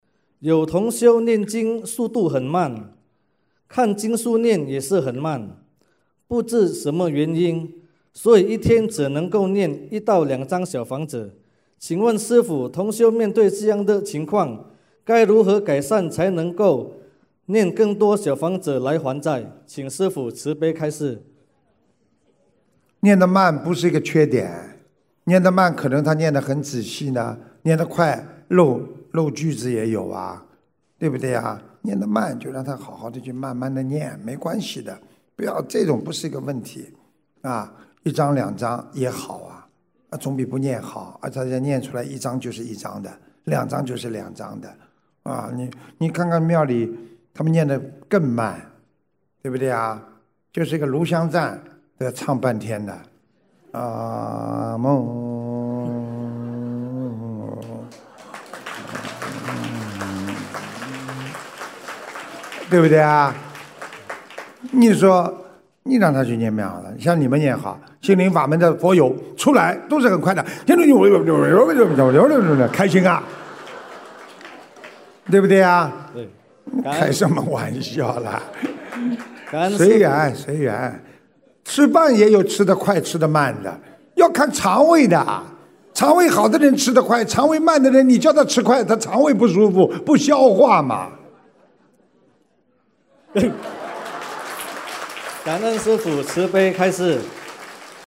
—— 2017年12月2日 新西兰・奥克兰法会